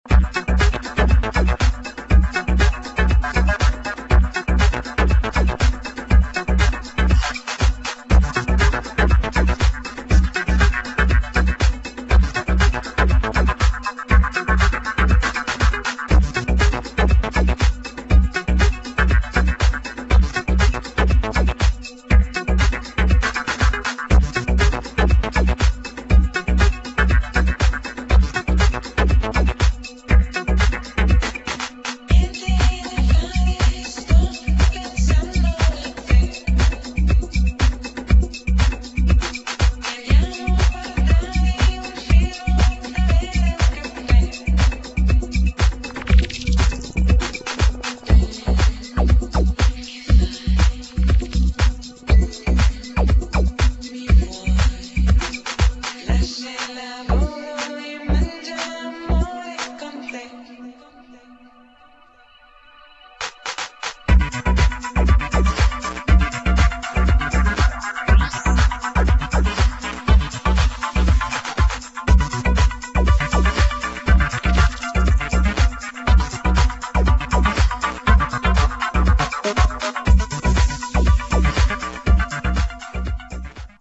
[ DISCO / HOUSE / ELECTRO / LEFTFIELD / ACID JAZZ ]